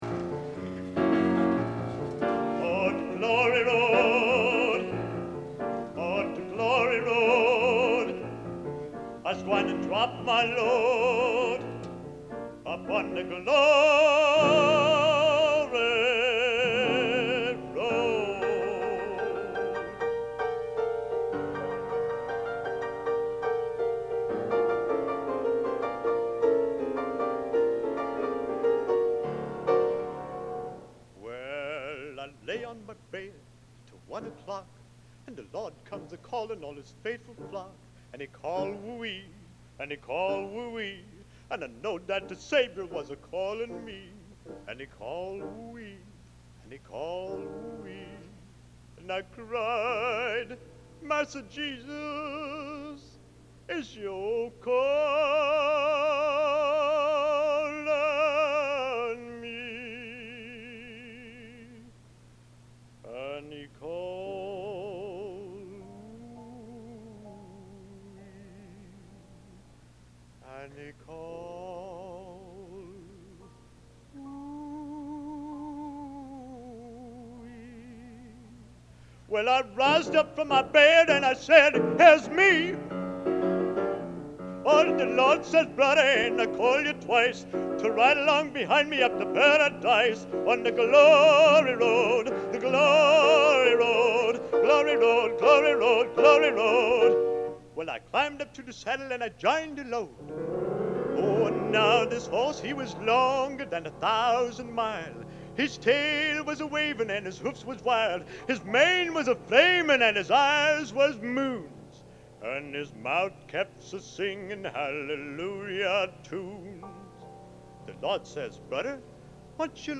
Location: Elliott Hall of Music, West Lafayette, IN
Genre: Gospel | Type: